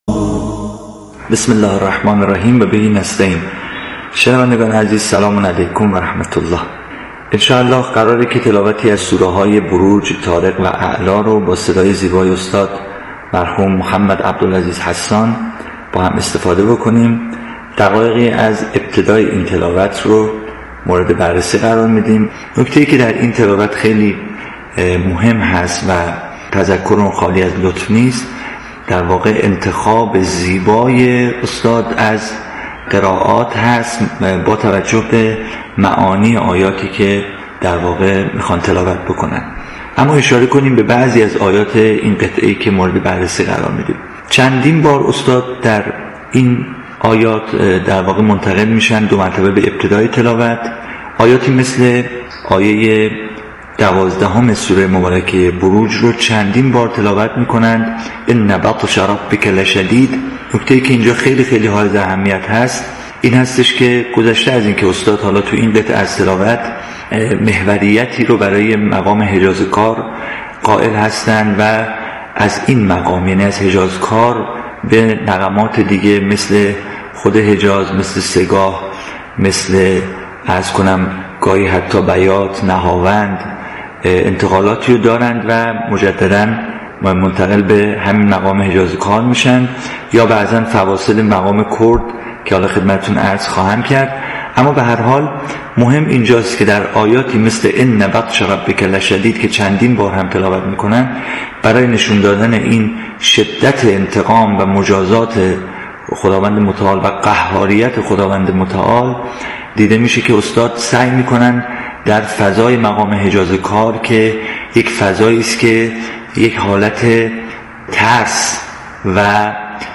فایل صوتی برنامه رادیویی اکسیر
یادآور می‌شود، این تحلیل در برنامه «اکسیر» از شبکه رادیویی قرآن پخش شد.